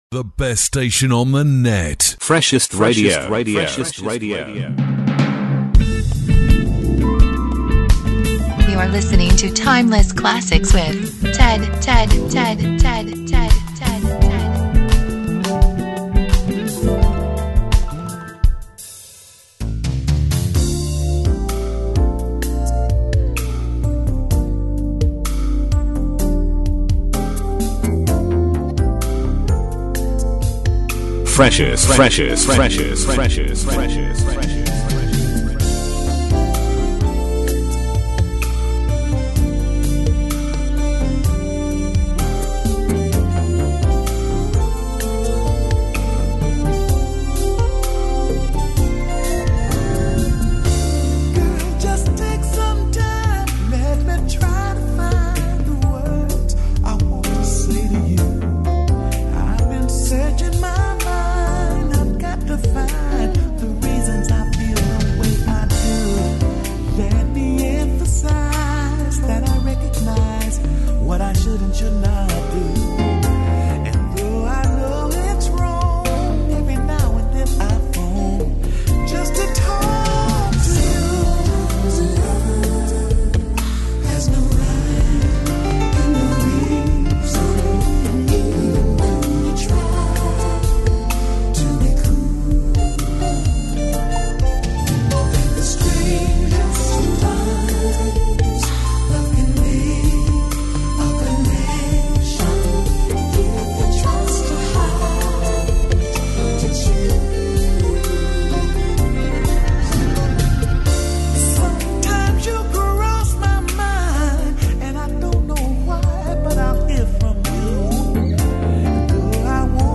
A show for classic R&B music lovers.